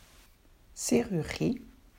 2. Serrurerie: Schlosserei (ßeerürrie)
Die Franzosen verschlucken dabei gekonnt die dritte Silbe („re“).